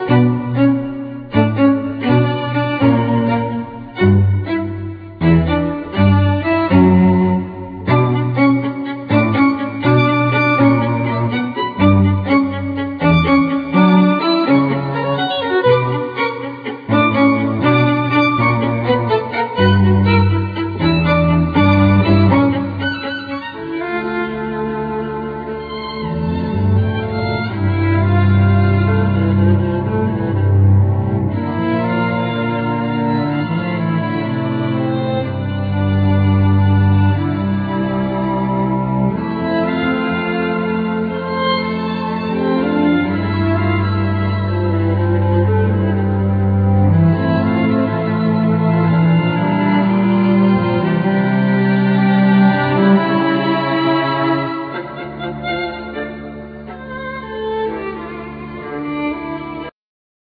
Guitar, Effect, Mix
Vocal, Piano, Programming
1st violin
2nd violin
Viola
Cello